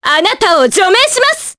Veronica-Vox_Skill3_jp.wav